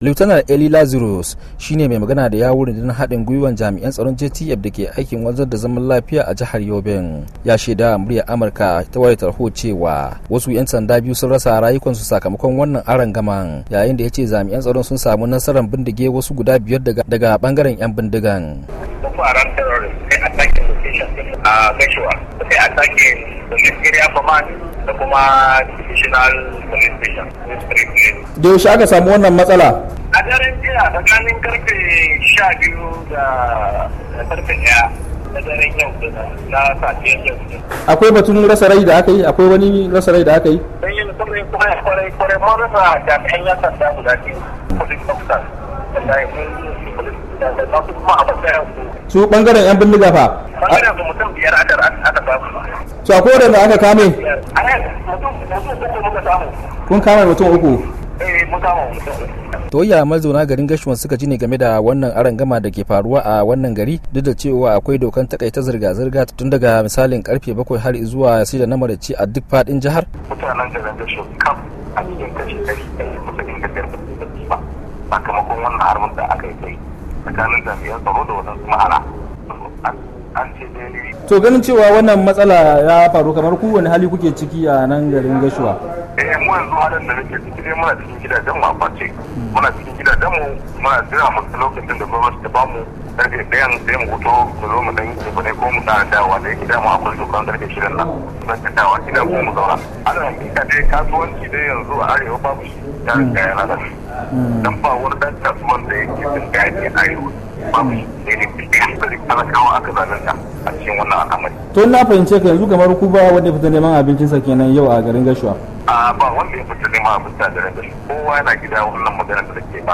Rohoto Akan Tashin Hankalin Da Akayi A Gashuwa - 2:20